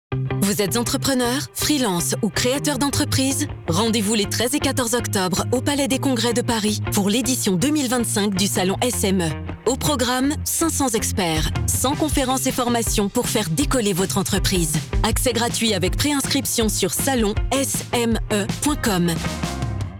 Le spot du salon 2025
Diffusé sur BFM Business du 22/9 au 13/10.